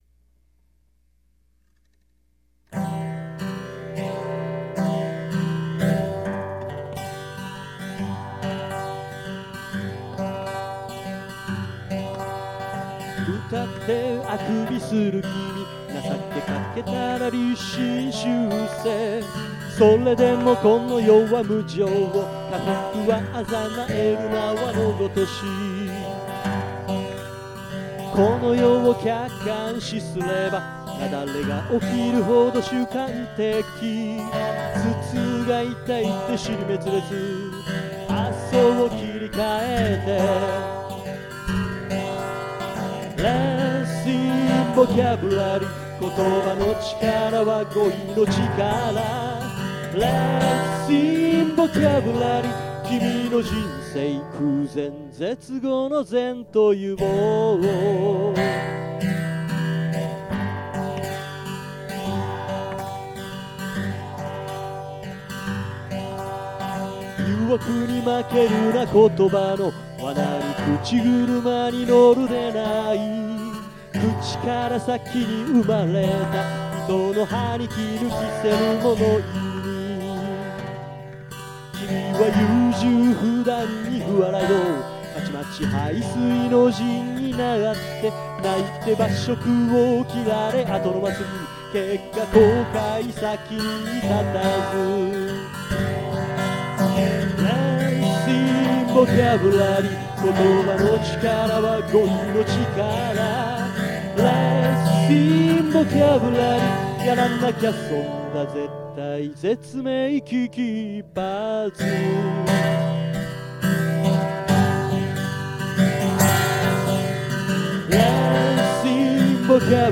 Let's　sing  ぼかぶりゃりー　は、歌になっています。